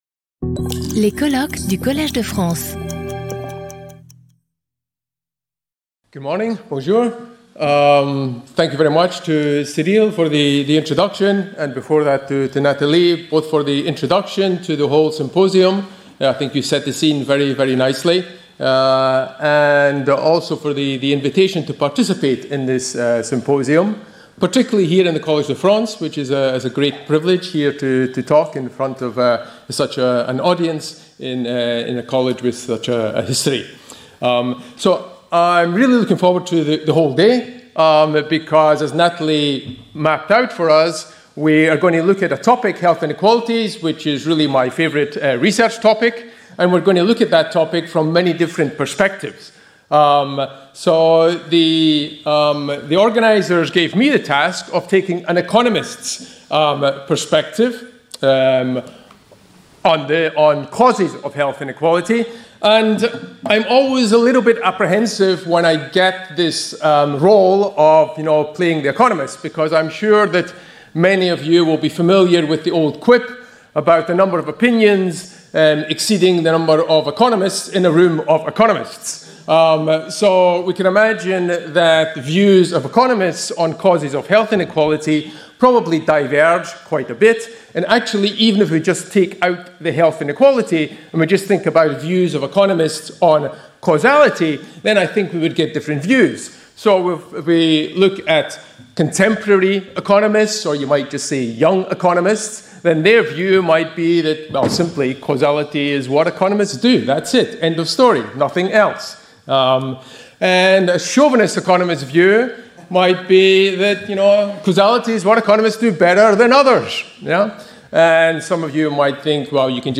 Sauter le player vidéo Youtube Écouter l'audio Télécharger l'audio Lecture audio Cette vidéo est proposée dans une version doublée en français.